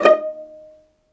VIOLINP .4-L.wav